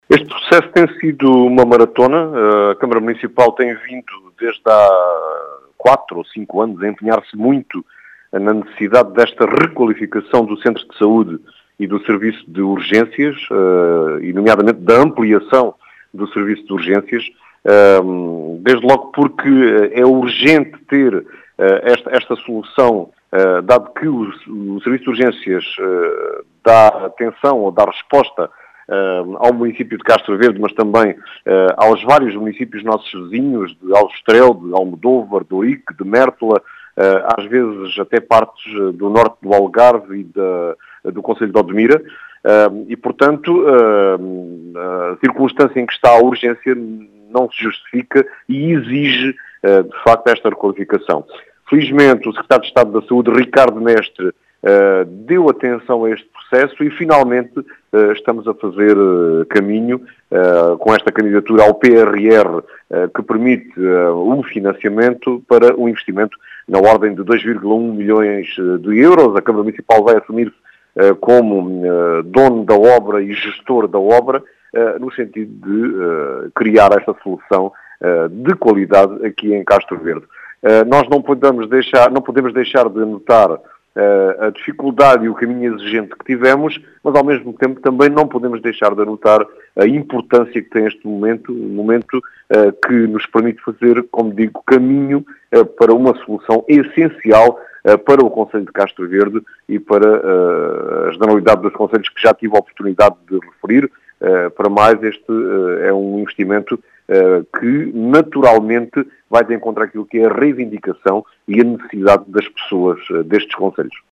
António José Brito, presidente da Câmara de Castro Verde, fala num processo que tem sido uma “maratona”, mas diz ser uma “solução essencial” para Castro Verde, e para os demais concelhos que utilizam o equipamento hospitalar, assinalando a importância deste momento.